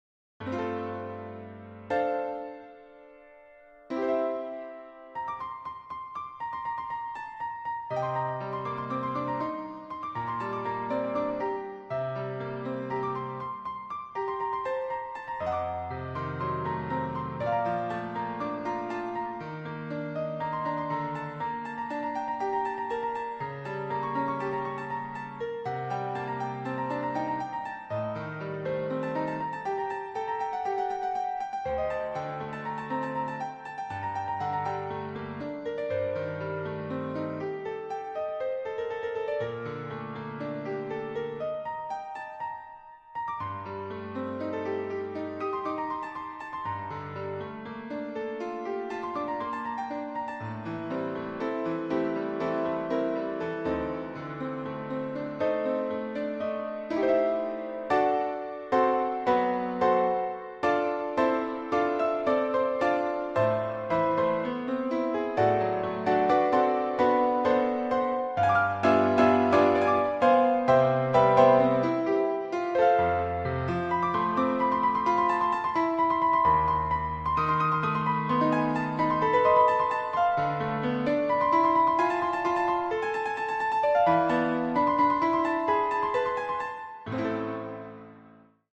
Müəllif: Azərbaycan Xalq Mahnısı